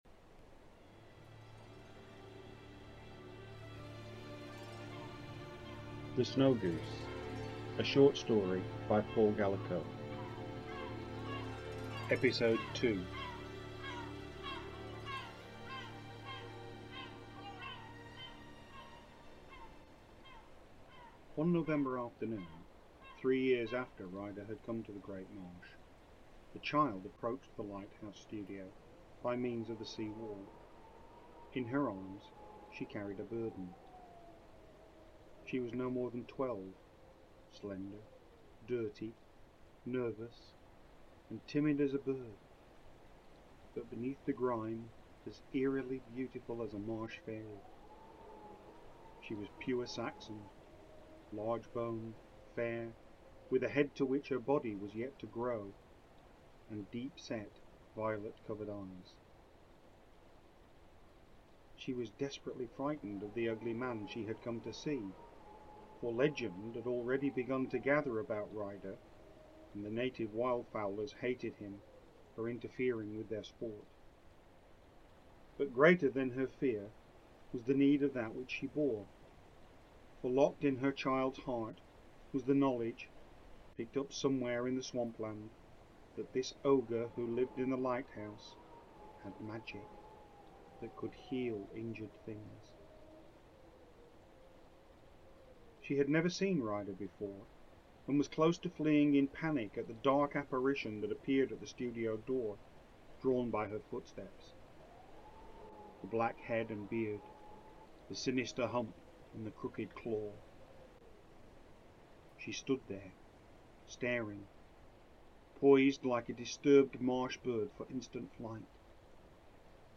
The Snow Goose: An Audiobook - Mortimer Dramatic Society